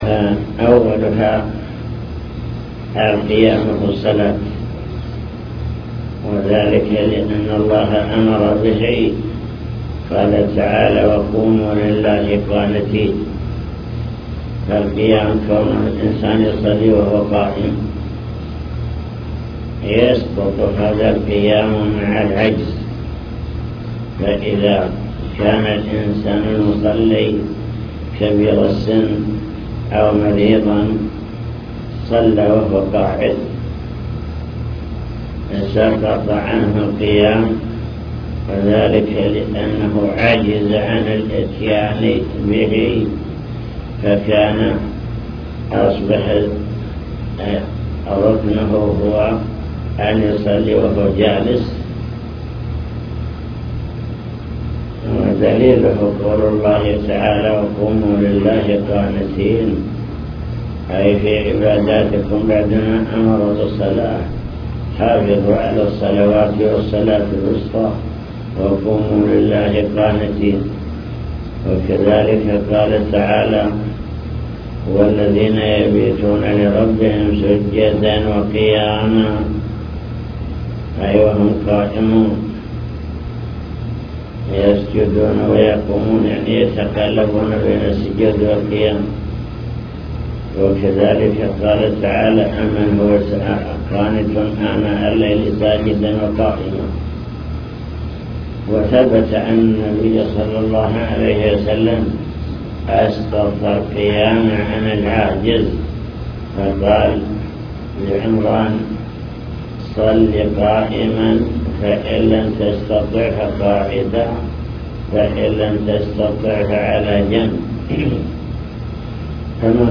المكتبة الصوتية  تسجيلات - لقاءات  حول أركان الصلاة (لقاء مفتوح)